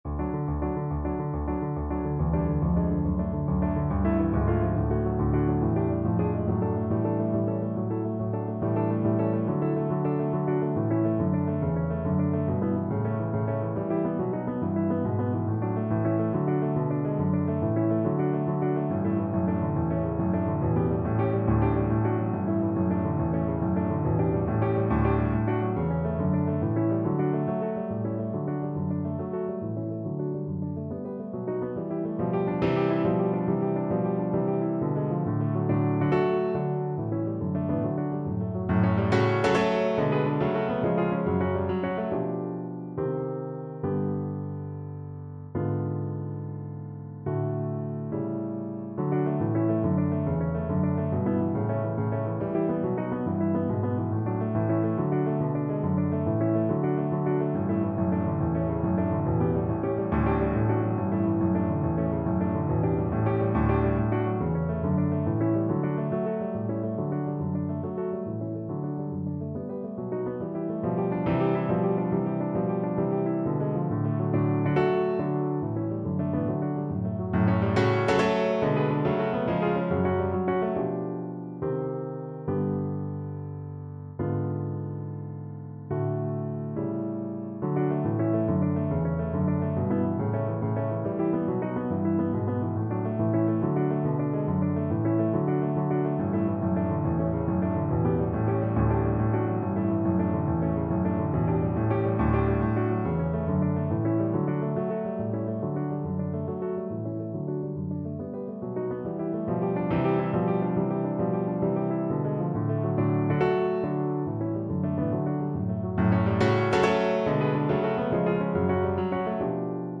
= 140 Geschwind
2/4 (View more 2/4 Music)
Classical (View more Classical Viola Music)